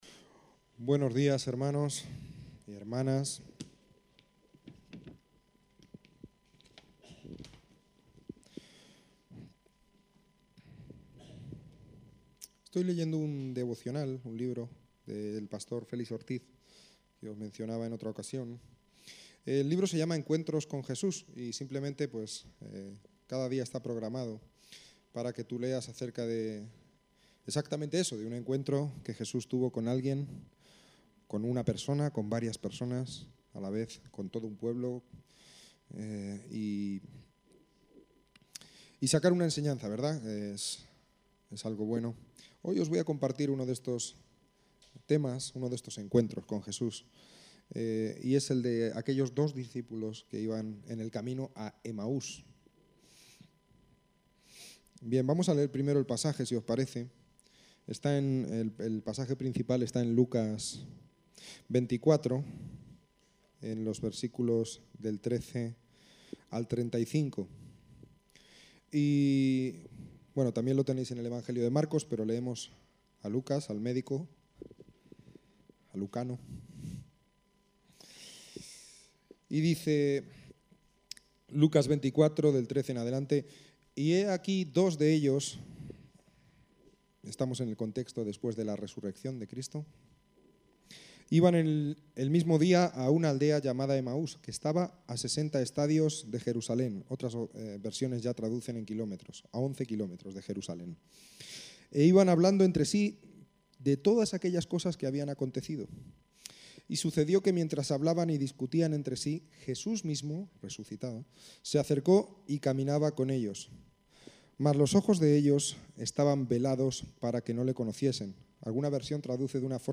Predicaciones